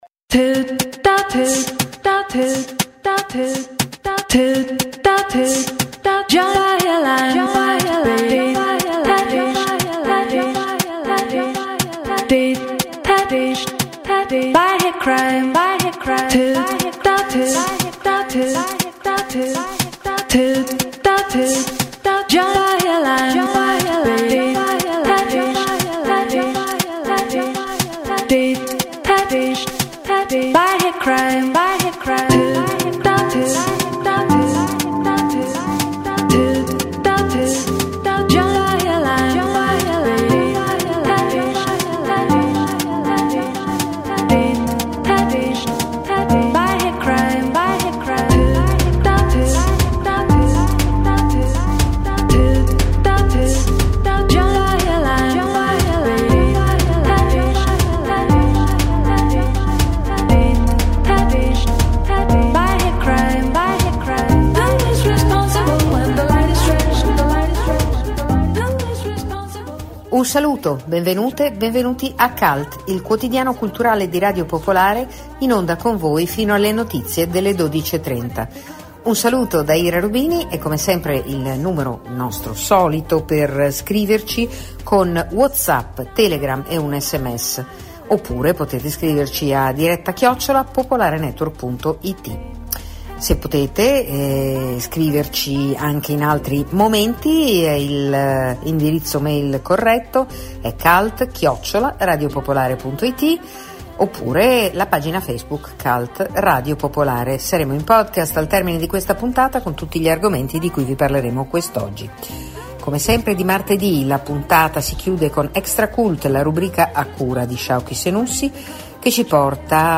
Cult è il quotidiano culturale di Radio Popolare, in onda dal lunedì al venerdì dalle 11.30 alle 12.30.